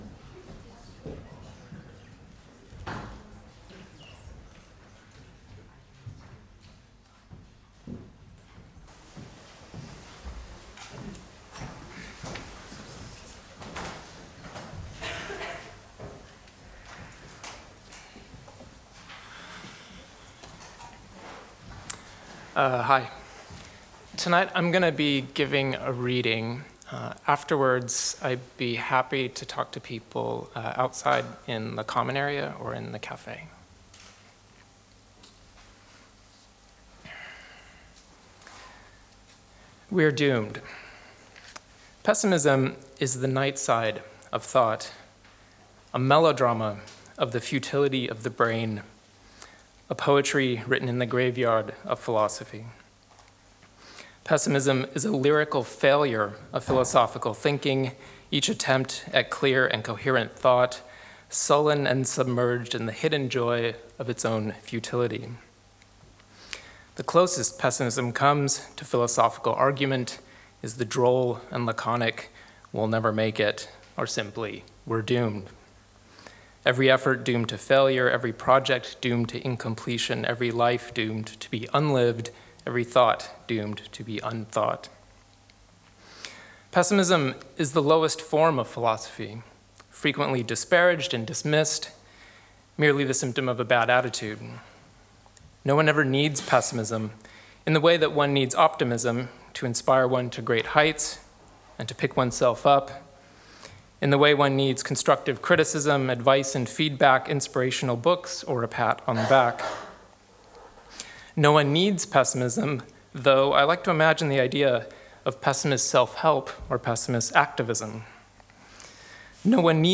A chat with Eugene Thacker. Can we rethink the world as unthinkable, and without us?